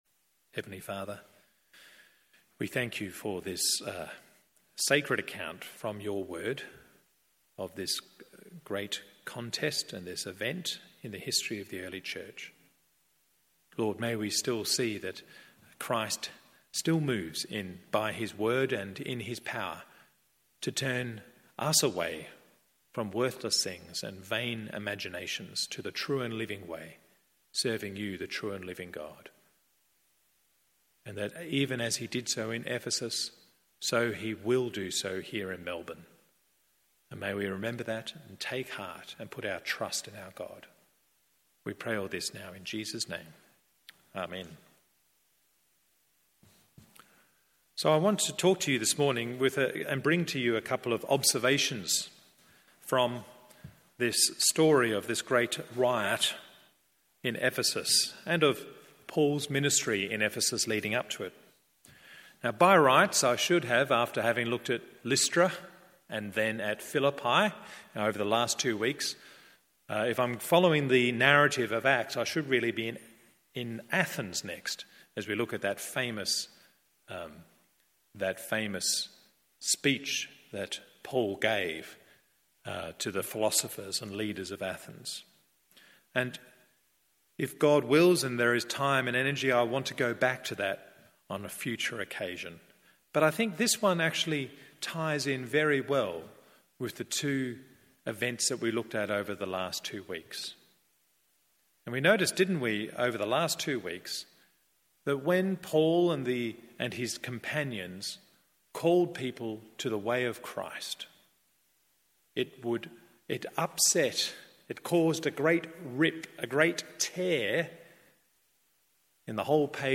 MORNING SERVICE Acts 19…